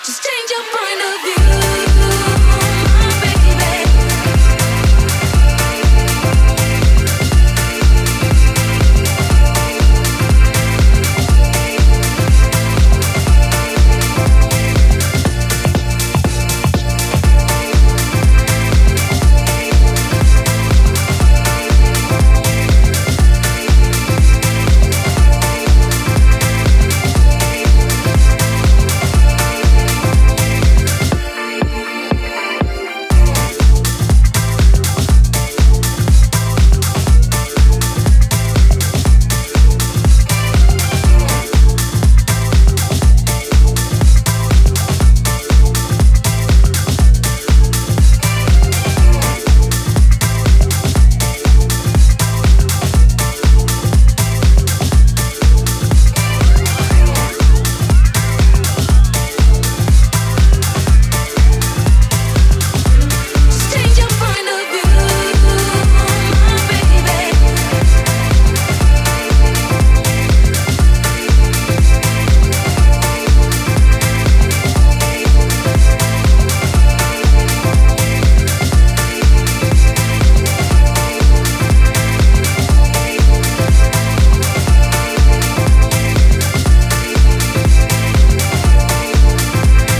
ディスコにインスパイアされた爽快ブギー・ディスコ・ハウス！
ジャンル(スタイル) DISCO / HOUSE